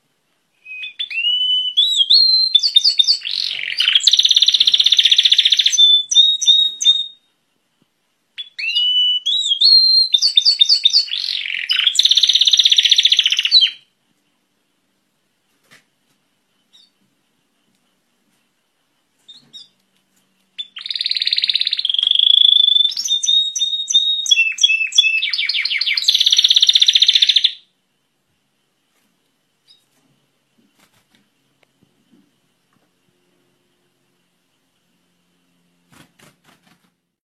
ringtone pajarito 1